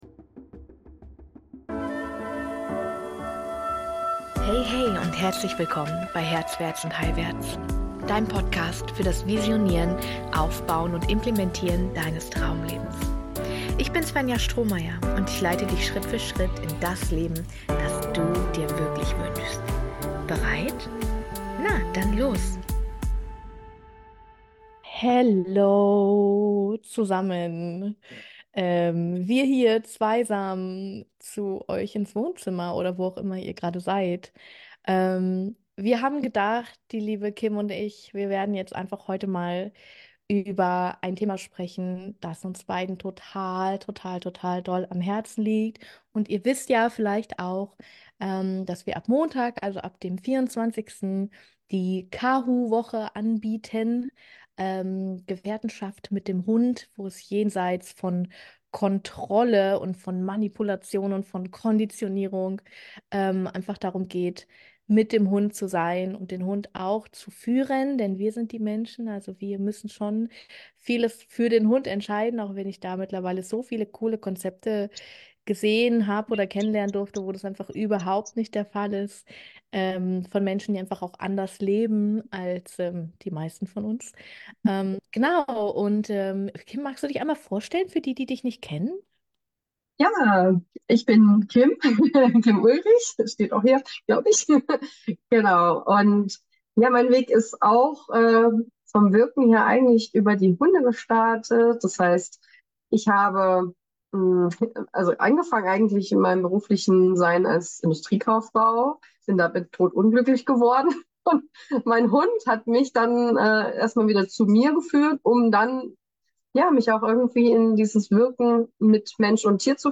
Wir nehmen dich in dem Gespräch, das ich heute mit dir teile, mit in unsere Erfahrungen mit unseren Gefährten.